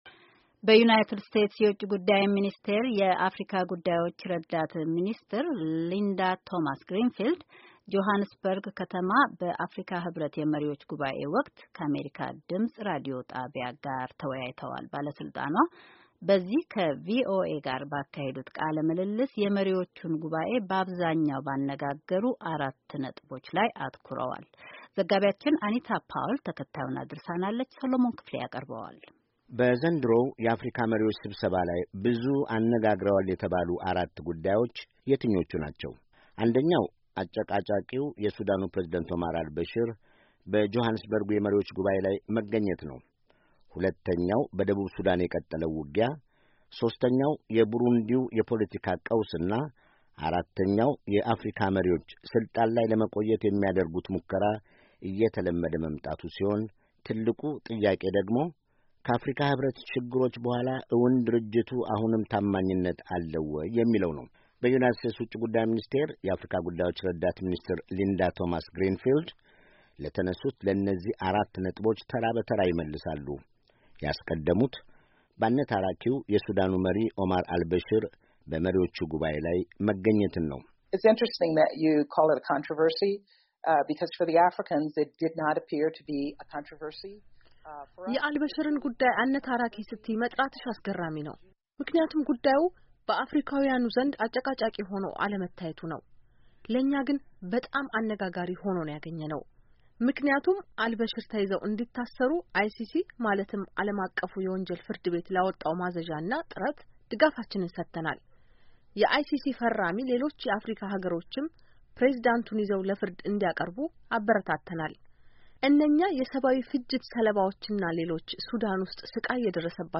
በዩናይትድ ስቴትስ ውጭ ጉዳይ ሚኒስቴር የአፍሪካ ጉዳዮች ረዳት ሚኒስትር ሊንዳ ቶማስ ግሪንፊልድ ጆሐንስበርግ ከተማ ላይ በቅርቡ ስብሰባ ባካሄዱበት ወቅት፥ ከአሜሪካ ድምፅ ራዲዮ ጣቢያ ጋር ተወያይተዋል። ባለሥልጣኗ በዚህ ቃለ ምልልስ፥ የመሪዎቹን ጉባዔ ባብዛኛው ባነጋገሩ አራት ነጥቦች ላይ አትኩረዋል። ነጥቦቹም አጨቃጫቂው የሱዳኑ ፕሬዘዳንት አል ባሺረመሪዎቹ ጉባዔ ላይ መገኘት፥ ሁለተኛው በደቡብ ሱዳን የቀጠለው የርስ በርስ ውጊያ፥ ሦስተኛው፥ የቡሩንዲው የፖለቲካ...